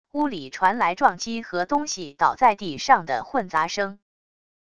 屋里传来撞击和东西倒在地上的混杂声wav音频